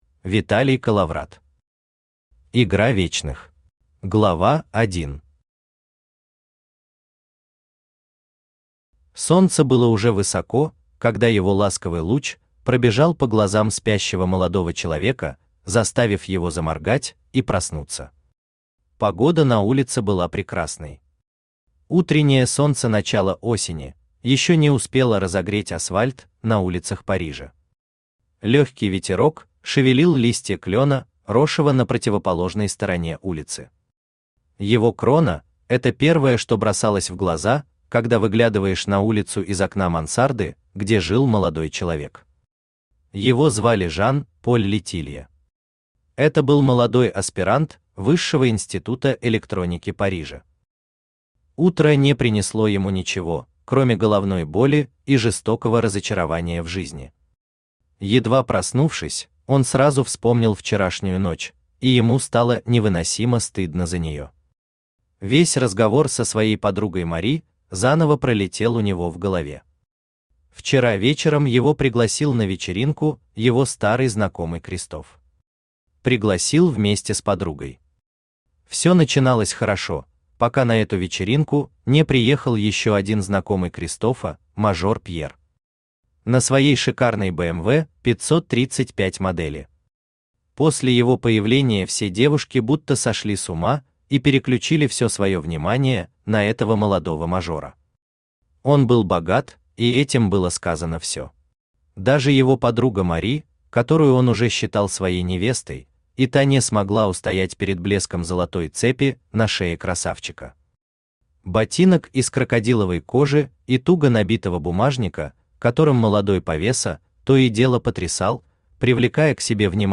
Aудиокнига Игра вечных Автор Виталий Колловрат Читает аудиокнигу Авточтец ЛитРес.